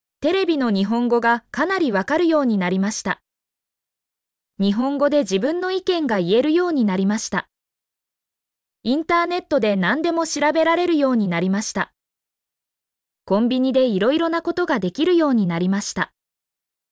mp3-output-ttsfreedotcom-6_Uy01Bqhg.mp3